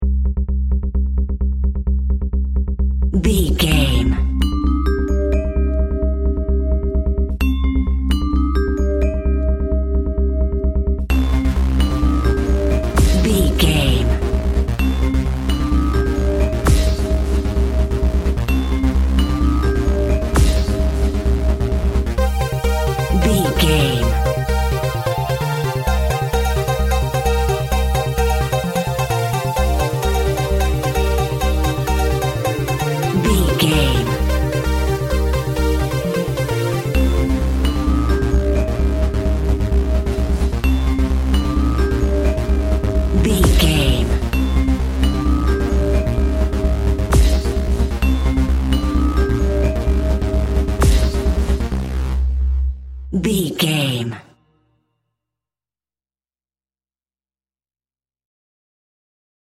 Fast paced
Aeolian/Minor
groovy
uplifting
energetic
funky
synthesiser
drum machine
breakbeat
synth lead
synth bass